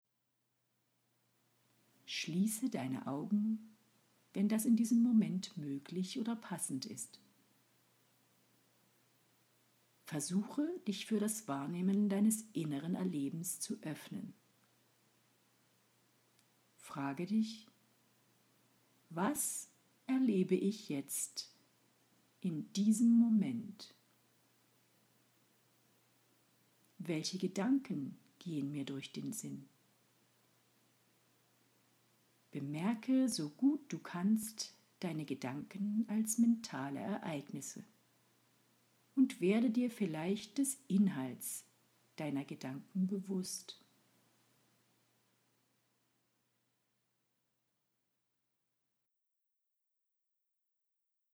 ACHTUNG! Am besten Du genießt die Aufnahme mit Kopfhörern, dafür ist sie optimiert!